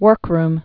(wûrkrm, -rm)